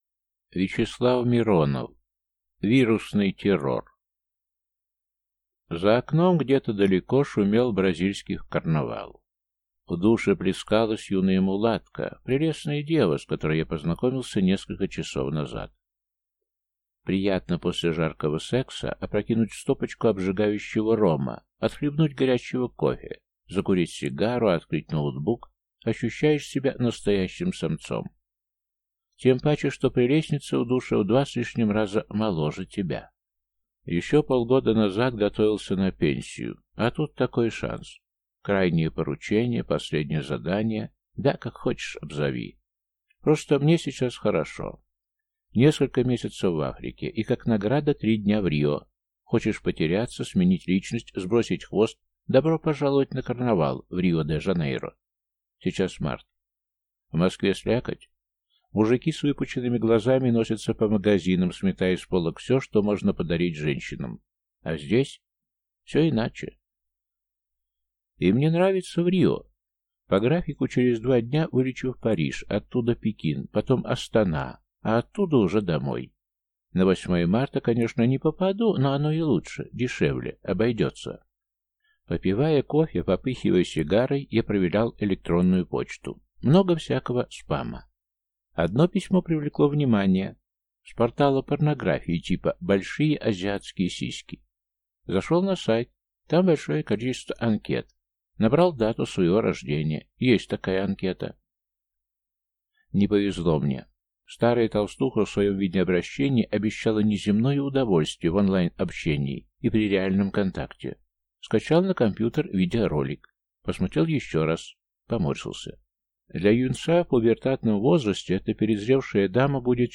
Аудиокнига Вирусный террор | Библиотека аудиокниг